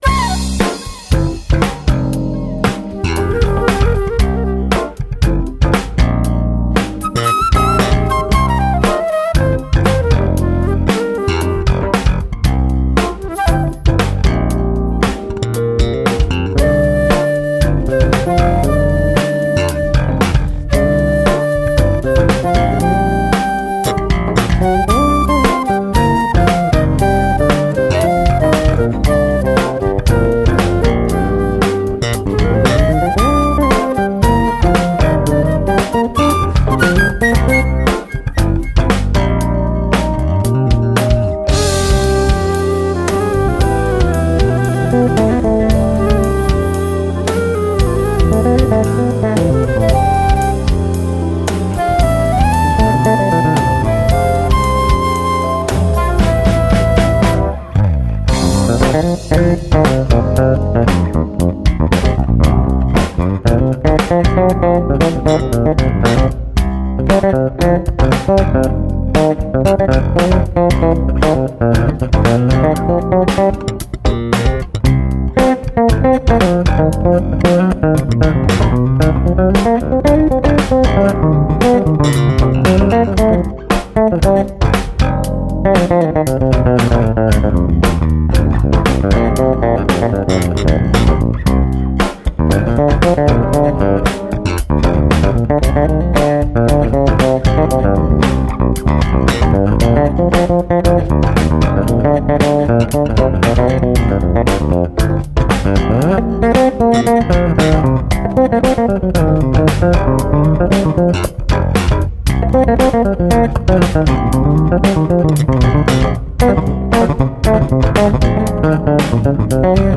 CD v súčasnom štýle groove-fusion-smooth-nu-...-jazz.
flauta, píšťalka, spev